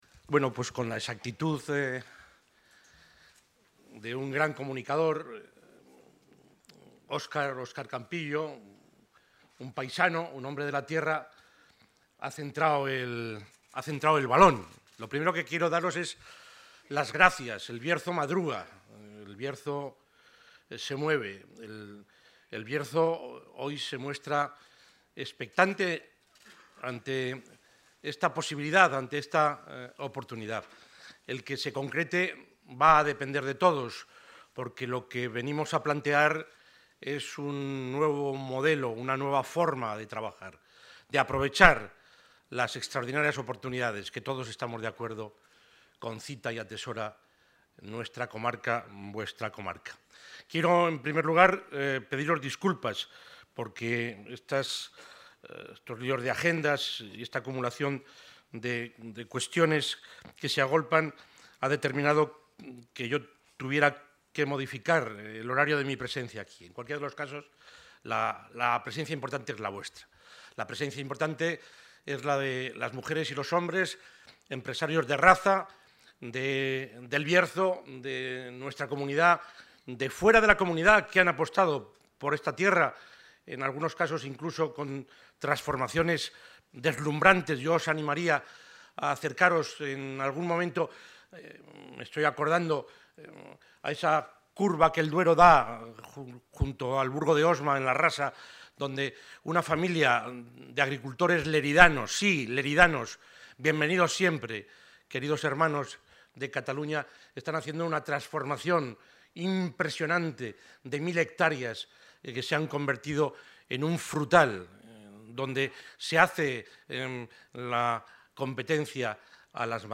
El presidente de la Junta de Castilla y León, Juan Vicente Herrera, ha presentado esta mañana en la localidad leonesa de Canedo el...